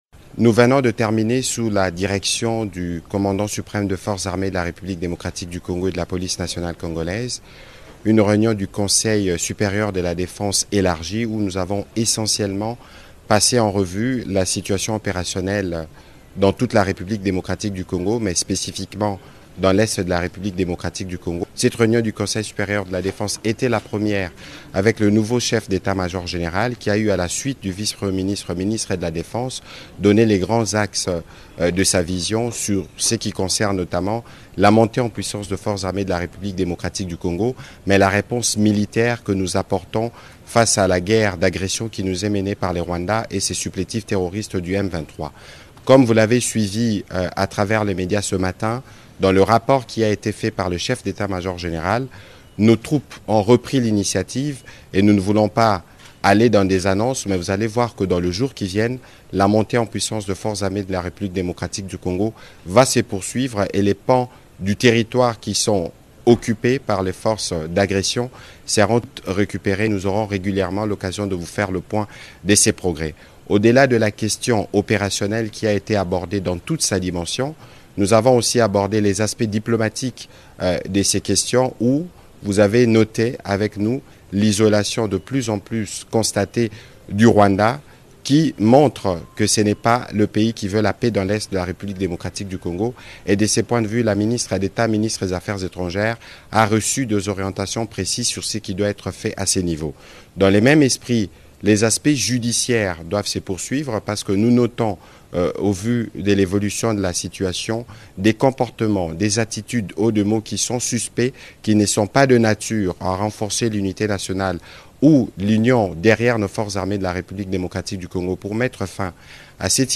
C’était à l’issue la réunion du Conseil supérieur de la défense élargi que le Chef de l’État congolais, Félix-Antoine Tshisekedi a présidé ce mercredi à la Cité de l'Union africaine à Kinshasa.